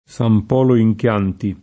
Sam p0lo ij kL#nti], già San Polo di Rubbiana [